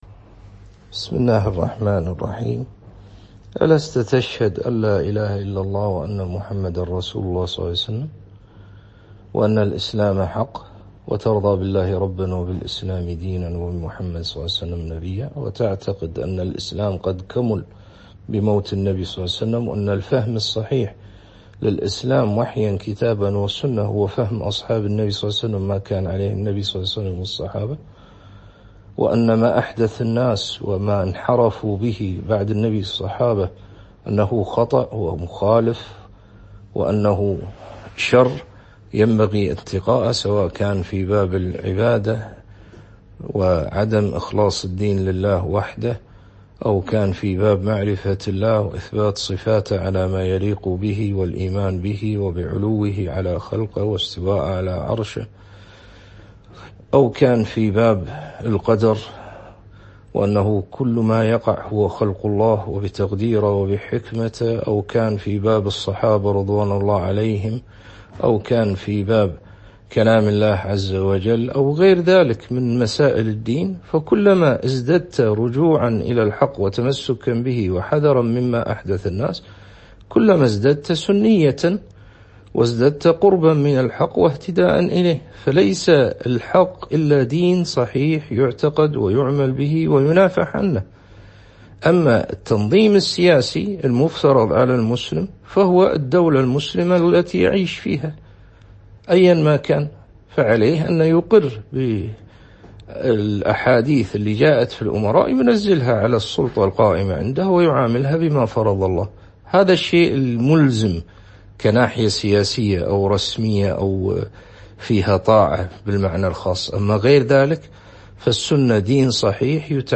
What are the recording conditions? Format: MP3 Mono 22kHz 32Kbps (ABR)